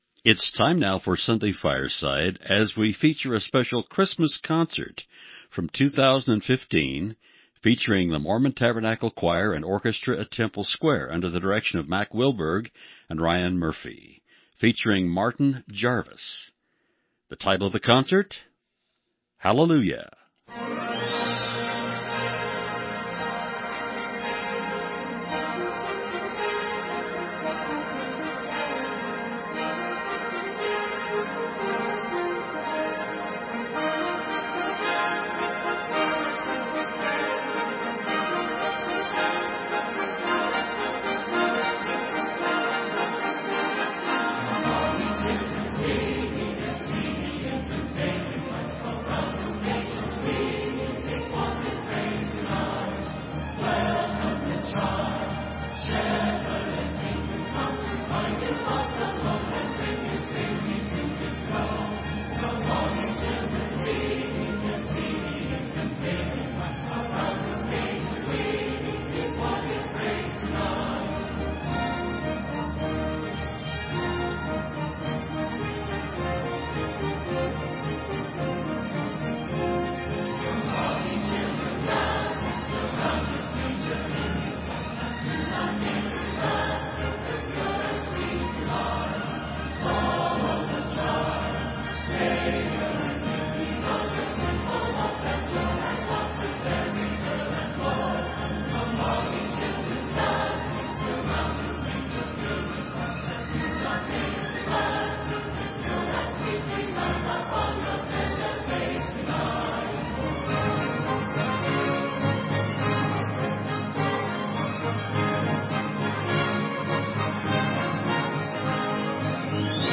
11-30-Tabernacle-Choir-Christmas-Concert-1.mp3